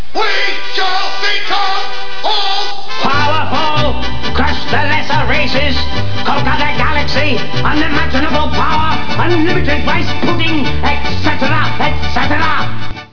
Some believe I've created another toe-tapping great, but a few critics feel I've strayed too far from my grunting funk roots.
HERE for a few seconds of the Doctor's newest cut, featuring a duet with Davros of the Imperial Dalek Quintet!